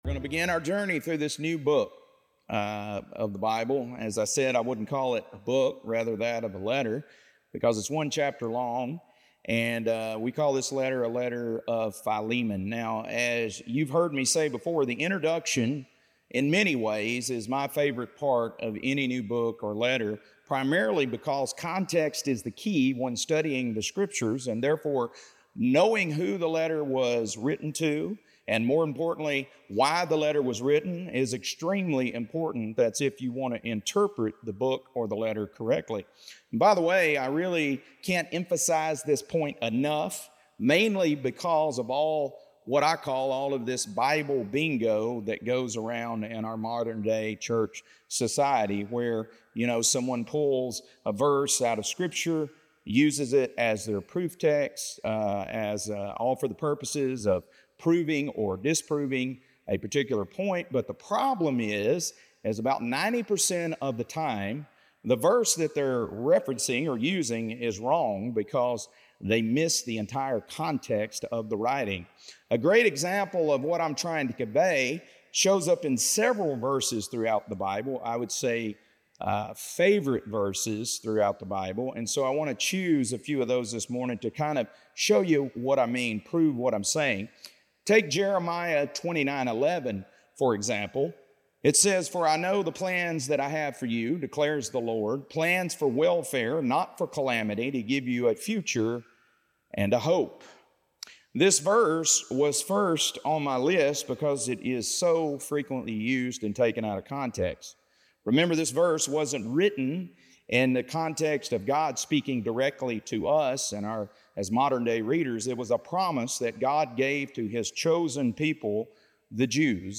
Welcome back everyone, this morning – we begin a new book of the Bible.